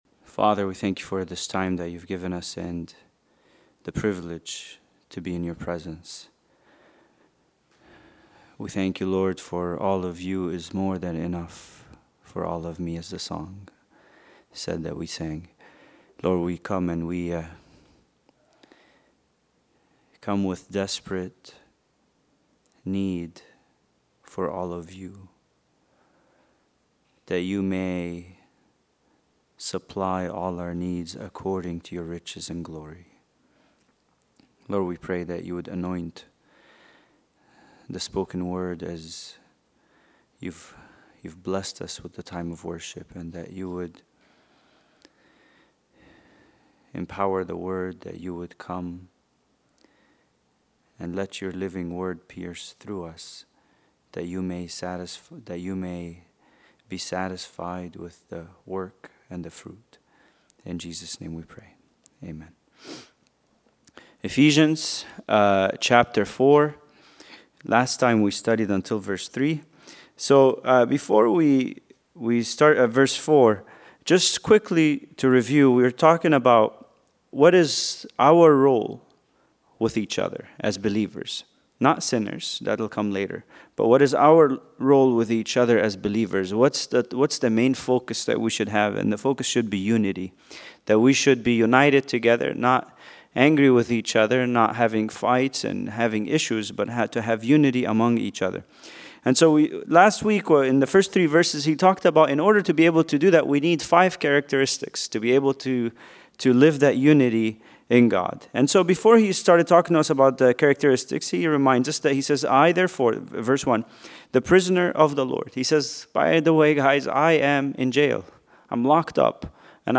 Bible Study: Ephesians 4:4-6